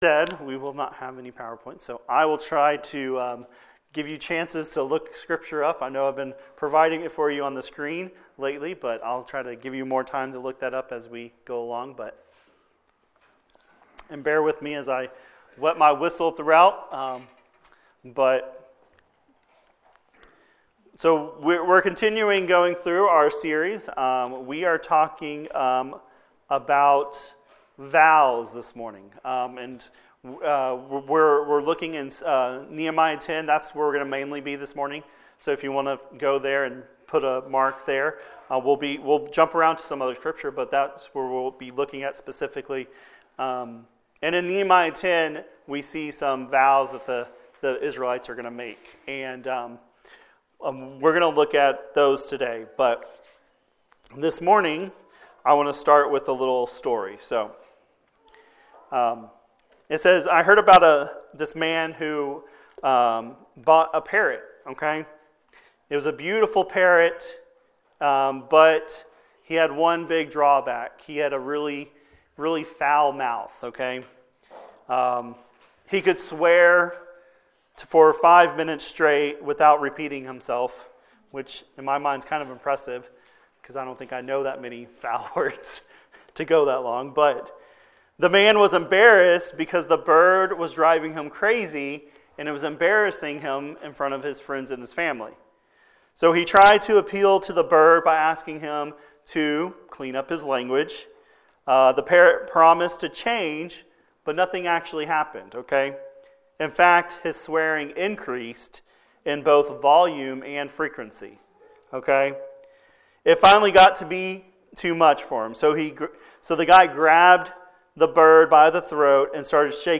wp-content/uploads/2023/02/Vows-of-the-People.mp3 A sermon from Nehemiah chapter 10.